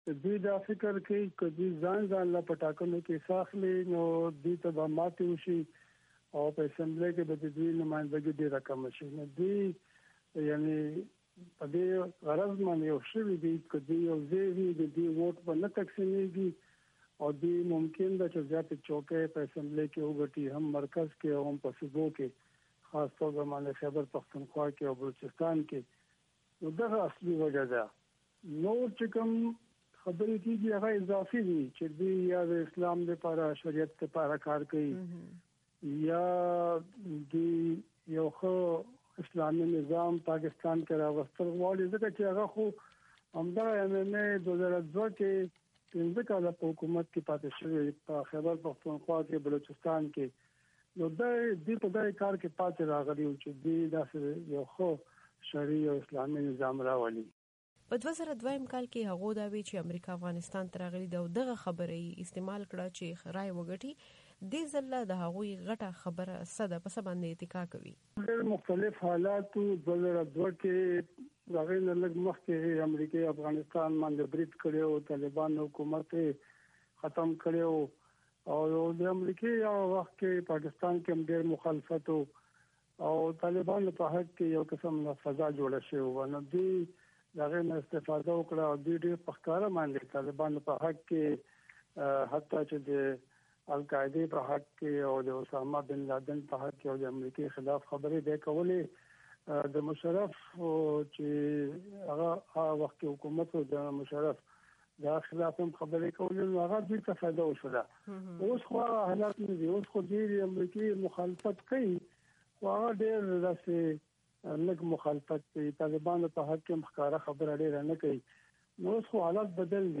د رحیم الله یوسفزي سره مرکه